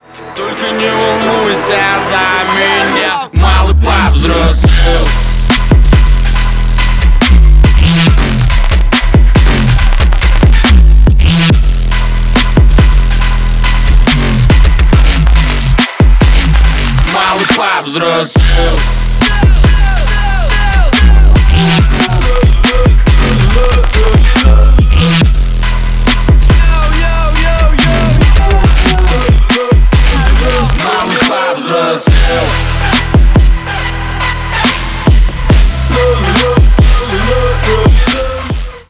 bass boosted , басы , громкие